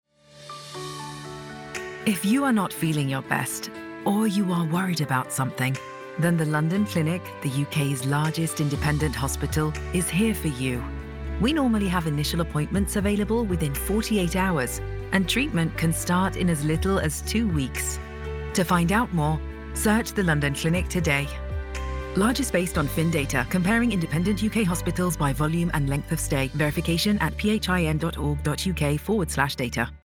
Tenho um estúdio de gravação profissional em casa e emprestei minha voz para uma ampla gama de projetos de alto nível.
ProfundoBaixo